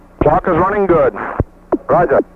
capcom_launch_misc_2.wav